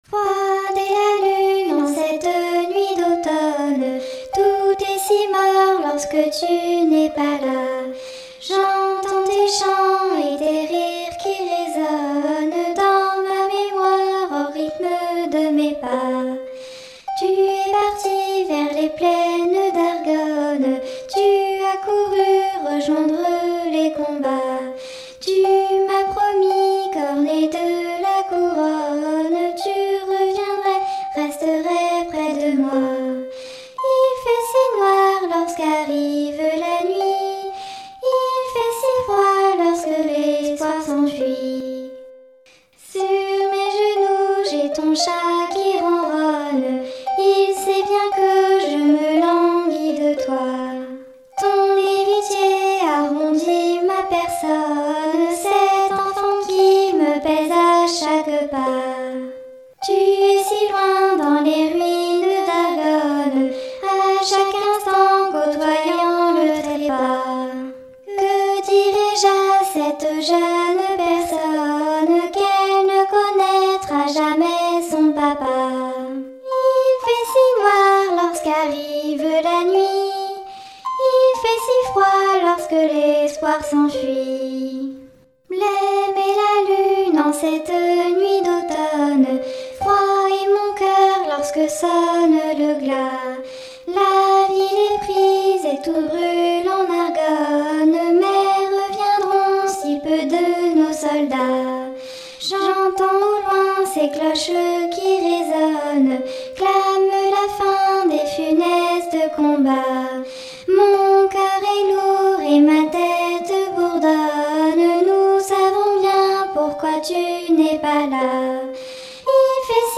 chant d'elfine.